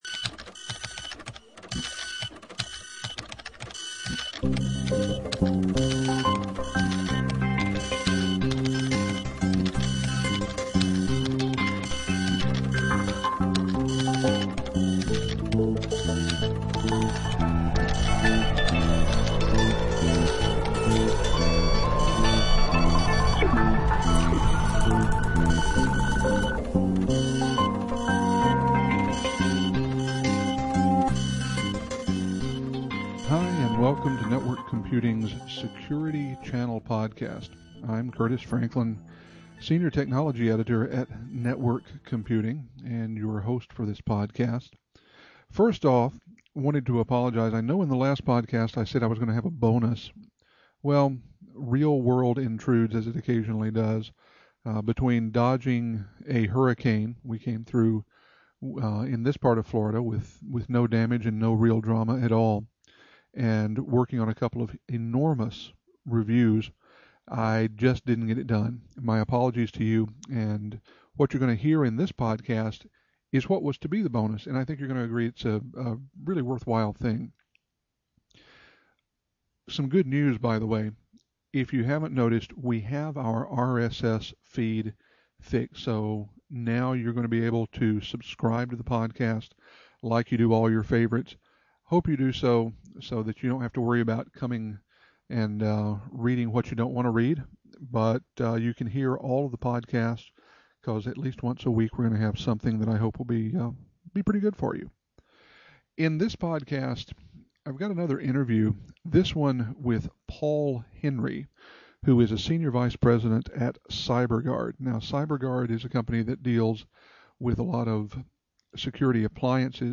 I've built a podcast on the interview.